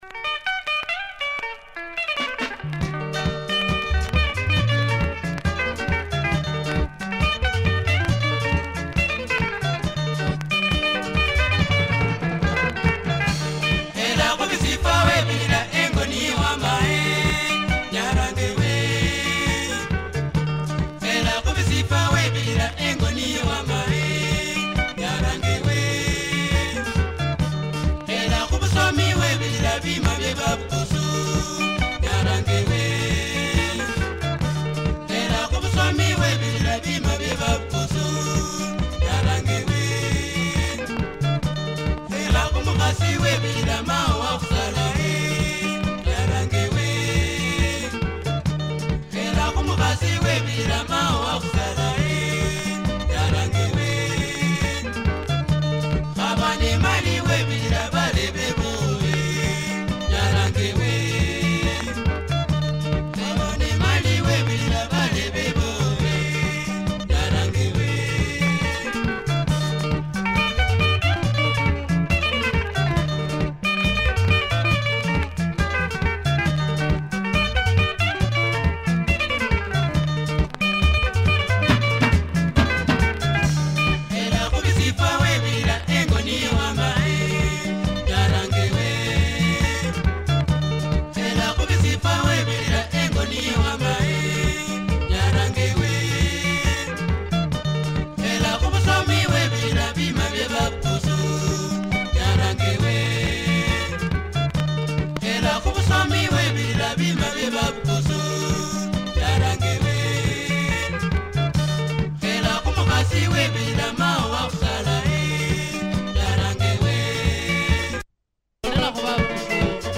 Uplifting Bukusa Benga